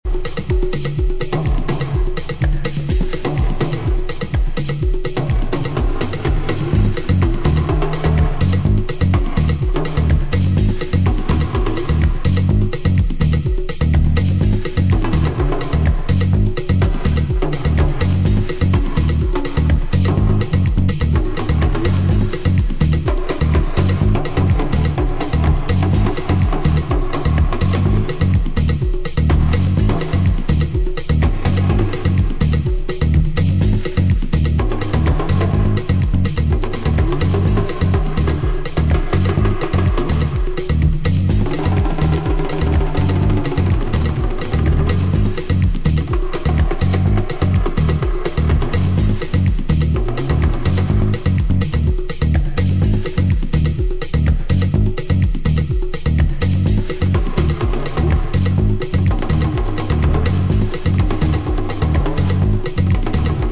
Amazing afro funk/house